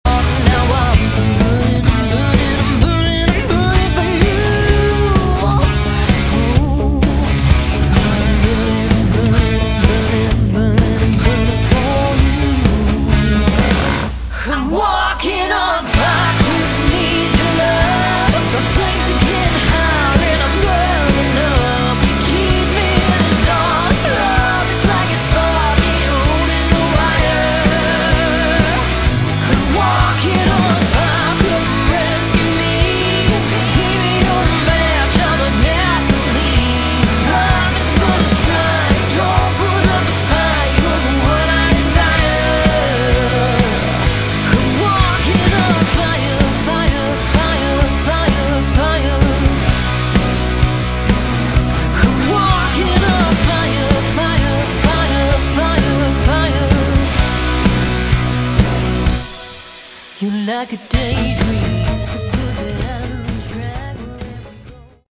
Category: Hard Rock
vocals
bass, keyboards, guitars, backing vocals
drums